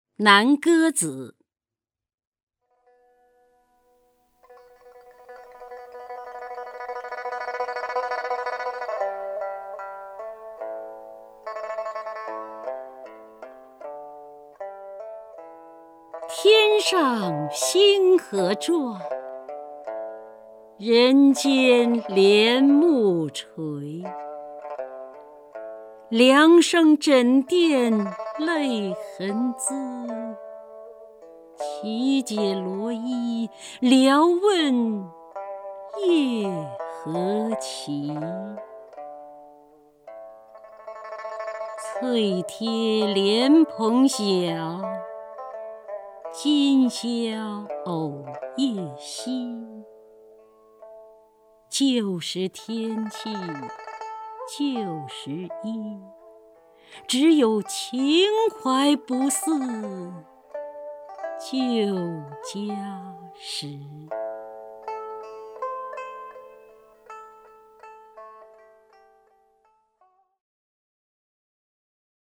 姚锡娟朗诵：《南歌子·天上星河转》(（南宋）李清照)
名家朗诵欣赏 姚锡娟 目录